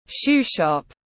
Shoe shop /ʃuː ʃɒp/
Shoe-shop.mp3